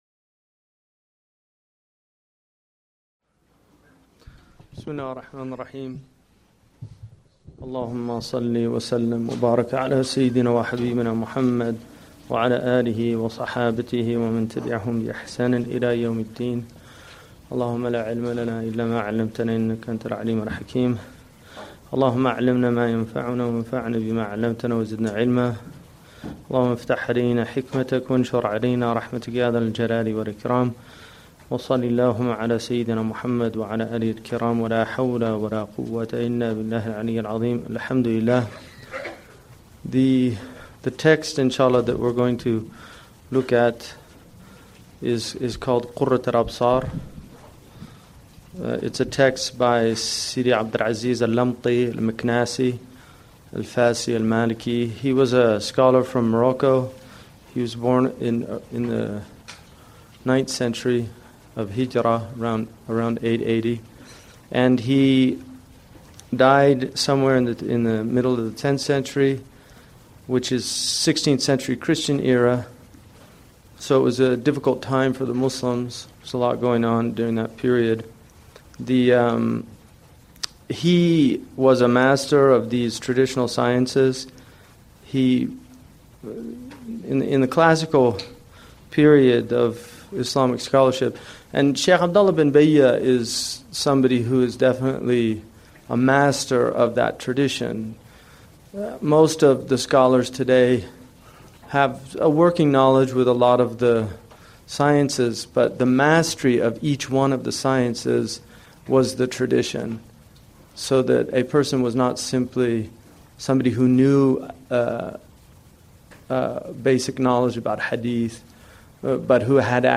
Qurrat al-Absar The Discerning Eye's Delight - Lesson 01： Shaykh Hamza Yusuf.mp3